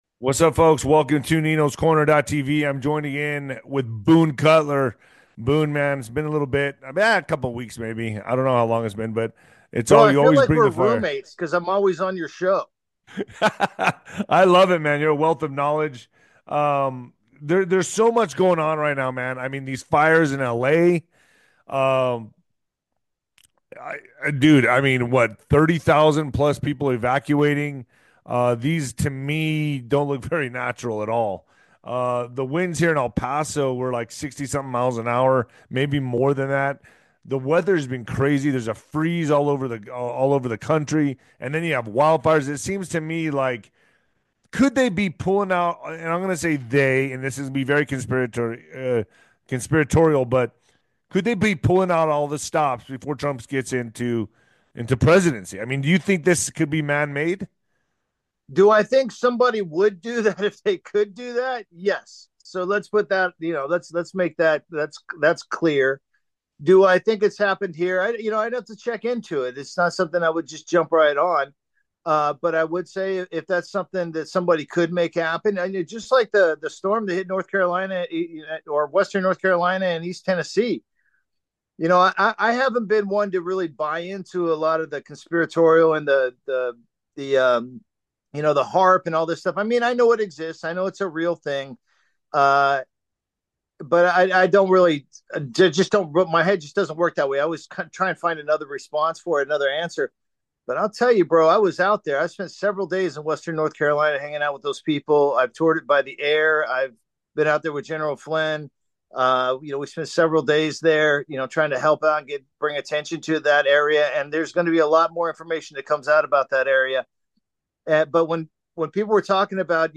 Nino's Corner TV hosts dive into hot topics like LA's wildfires and strange weather, wondering if they're man-made. They chat about politics, China's growing power, and why gold matters.